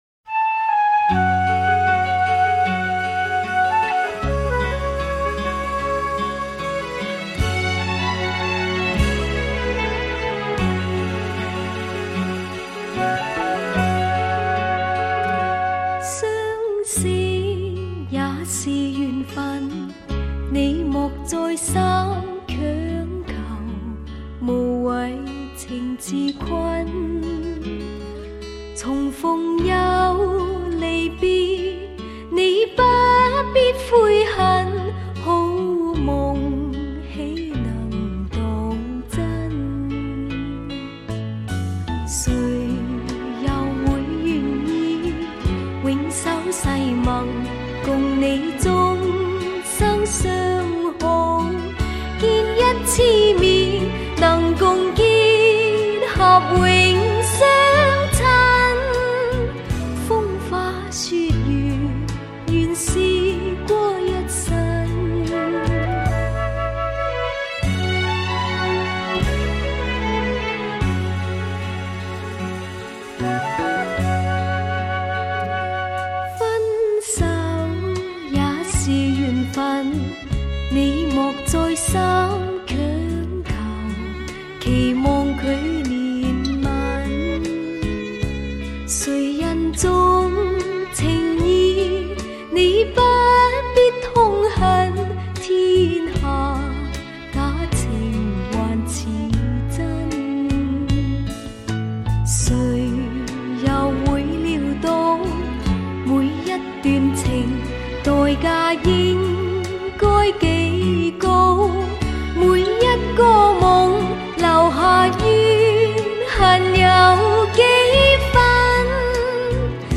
本碟采用美国SRS 3D音响技术
重拾保留隐藏在原录音带内之方位讯号
制作此超级立体HIFI真存20CD